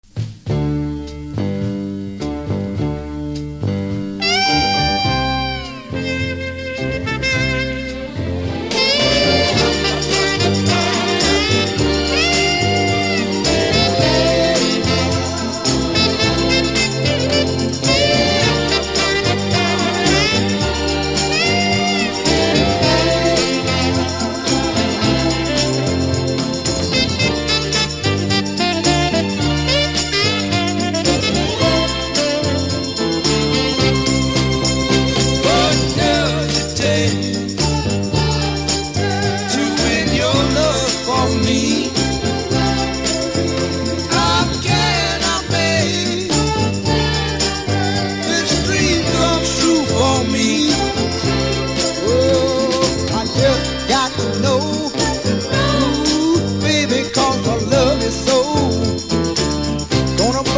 SOUL
多少キズありますが音には影響せず良好です。